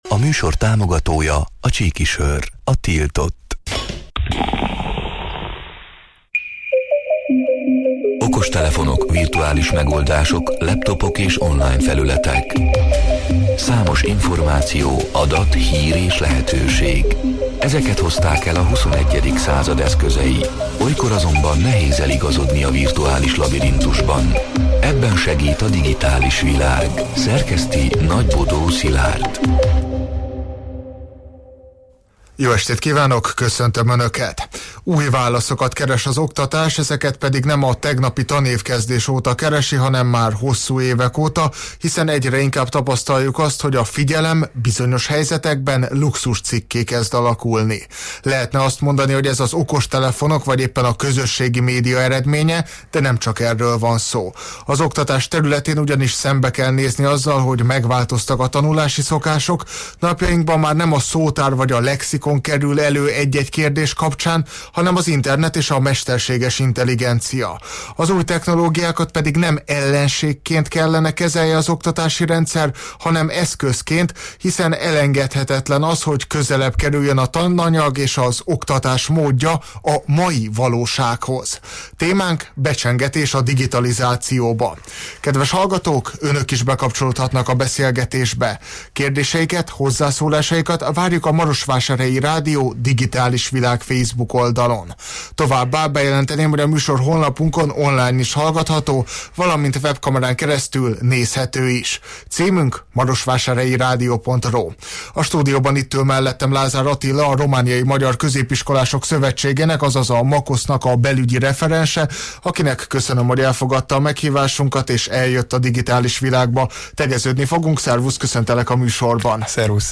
A Marosvásárhelyi Rádió Digitális Világ (elhangzott: 2025. szeptember 9-én, kedden este nyolc órától élőben) c. műsorának hanganyaga: